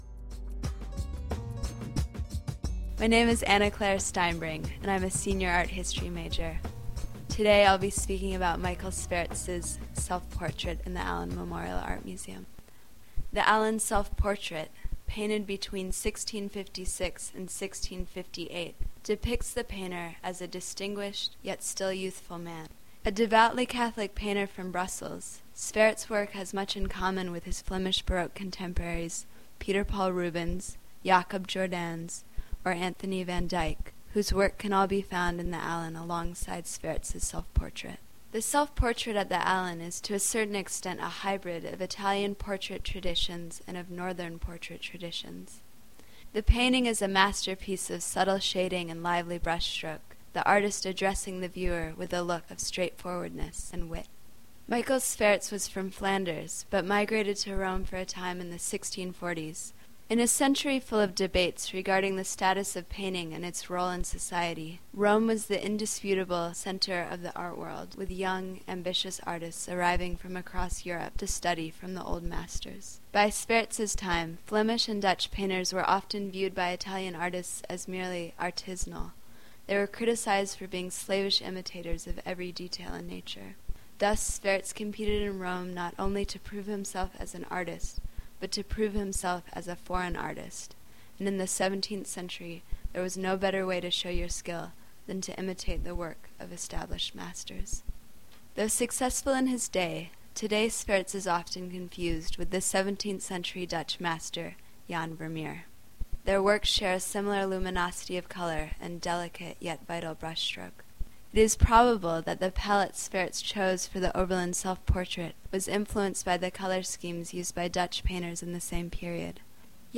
Here, professors and students alike discuss collection works – often through the lens of their individual disciplines.